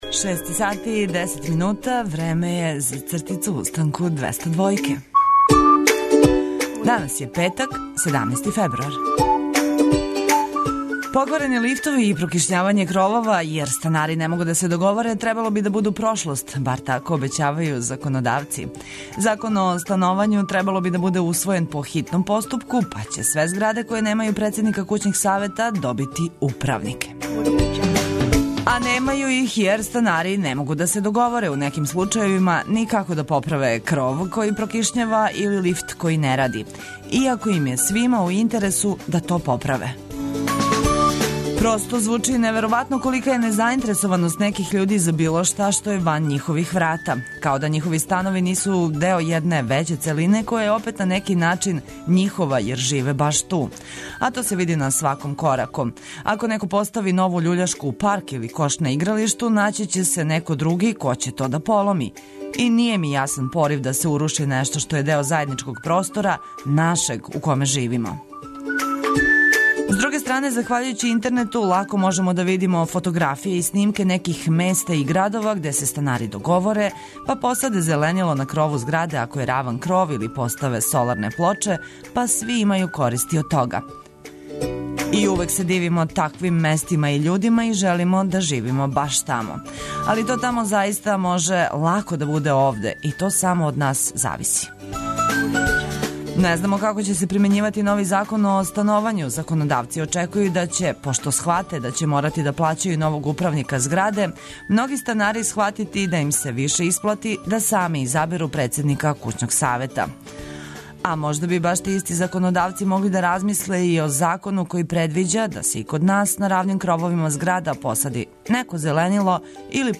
Зато смо ми и овог јутра са Вама, да се заједно будимо уз добру музику, оне најважније информације и понеки осмех.